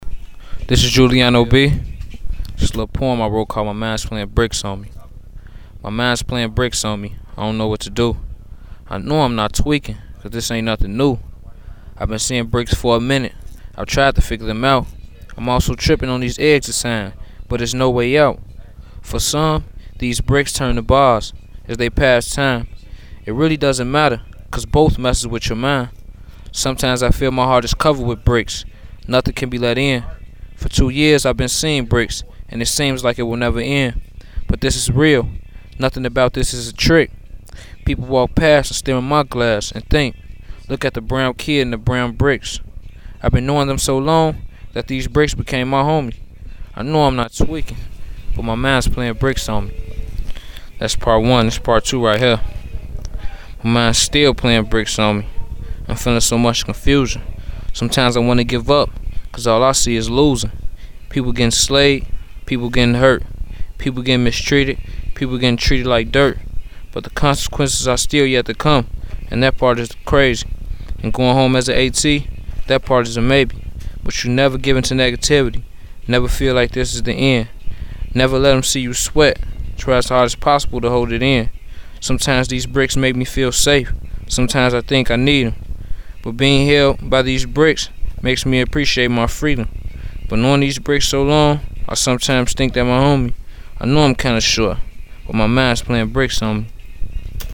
You can listen here to a powerful poem written and read by a young man who is incarcerated but has been given a chance through Free Write to share his feelings and thoughts.